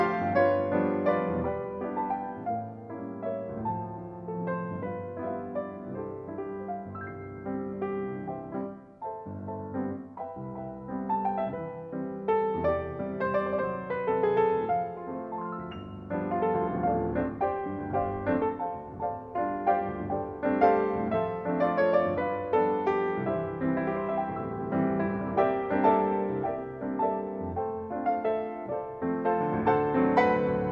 4 -- Valse improvisée dans le style années 20 (scène du bal)